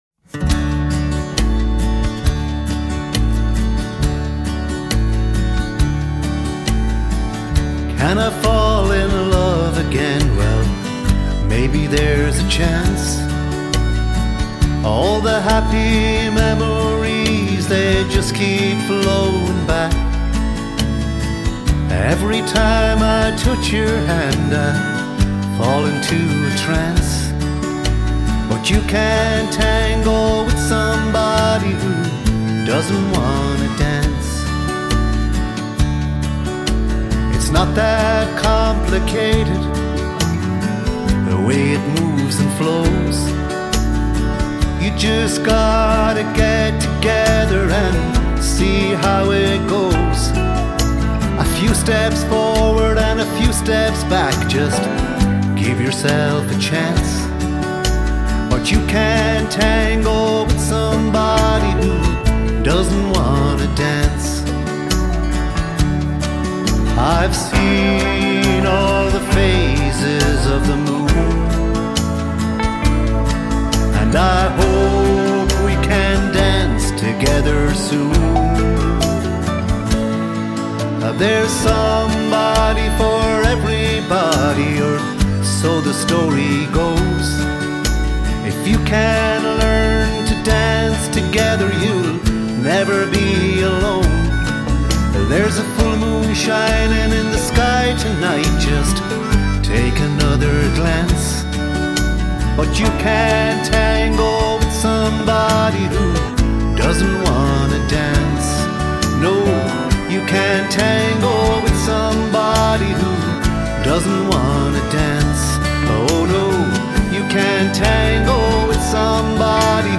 Guitar, Bodhrán, Harmonica, Vocals
Polished and beautifully performed